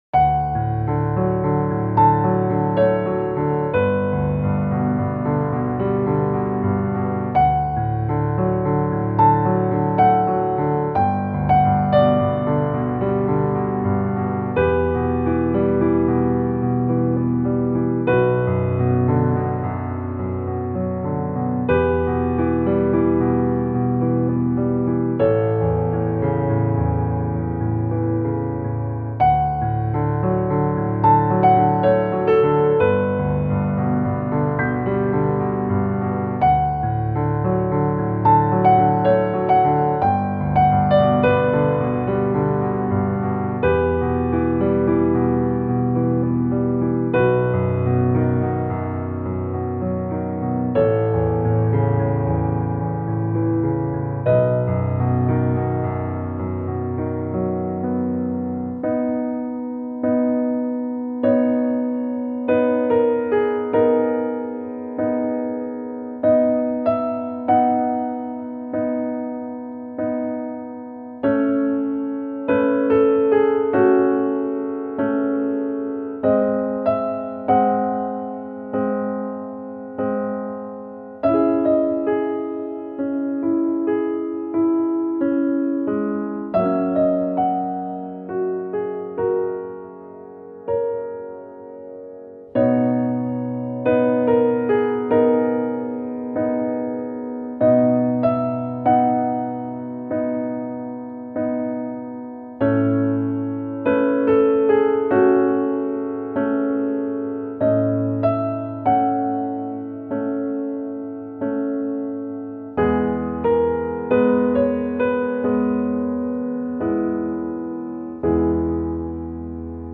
• 暗めのしっとりしたピアノ曲のフリー音源を公開しています。
なだらか 癒し 安らぎ